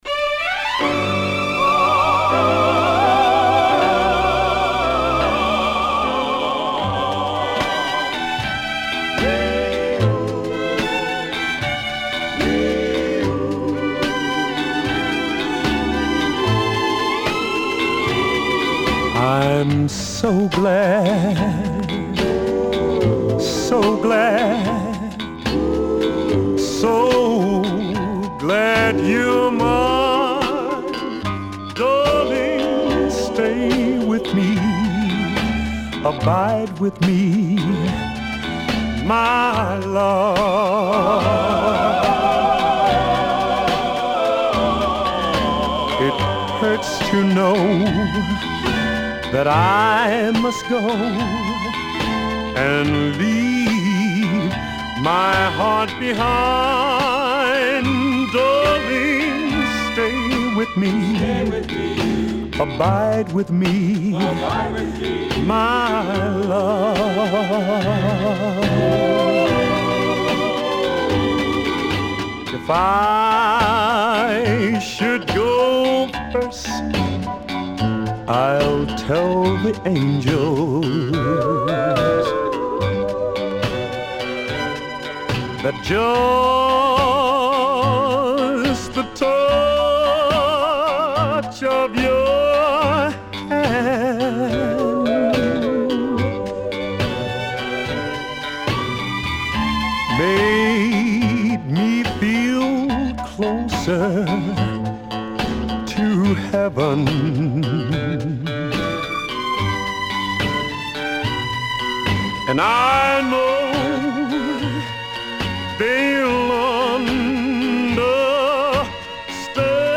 CONDITION SIDE A:VG(OK)
SIDE A:こまかい傷多いですがノイズあまり目立ちません。